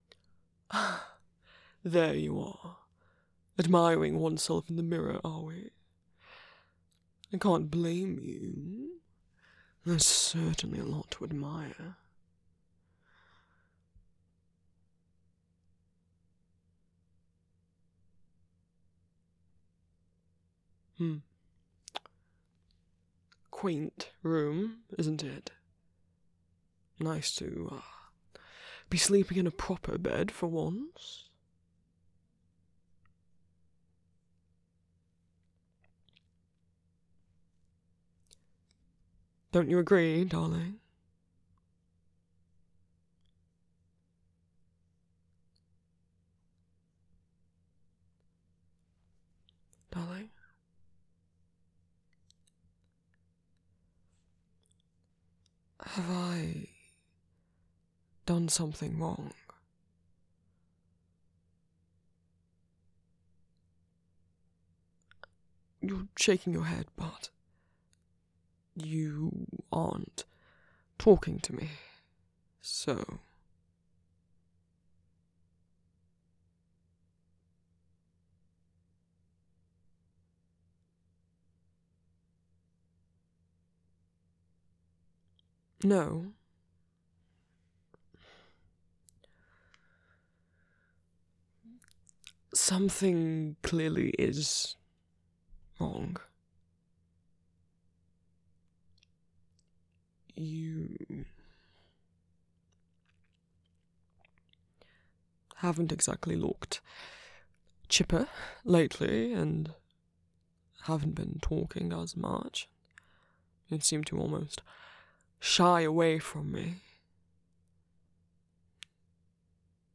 [M4TM] [Established relationship] [Romantic] [Kisses] [Gender affirmations] [Comfort] [TW references to dysphoria, transphobia, and Astarion's past abuse and trauma]